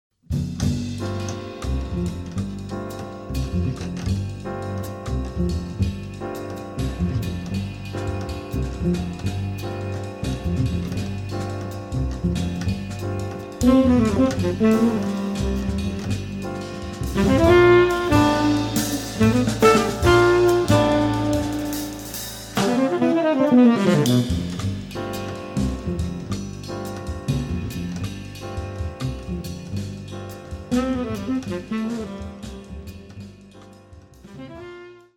sax
guitar
piano
bass
drums